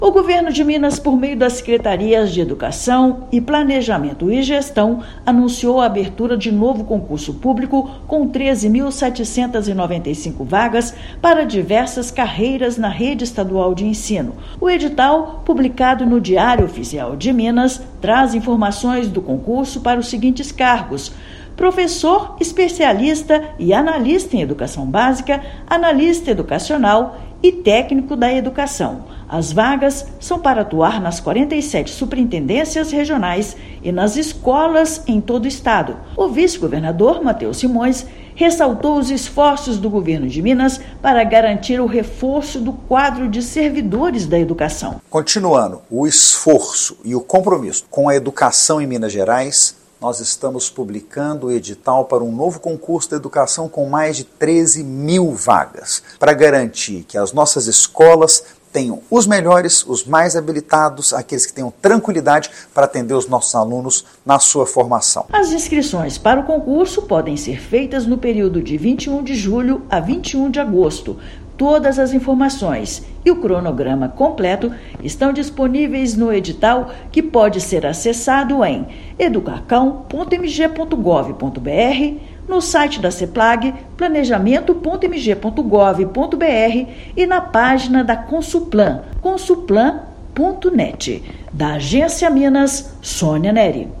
Edital publicado contempla diversas carreiras da rede estadual; inscrições abrem no próximo mês de julho. Ouça matéria de rádio.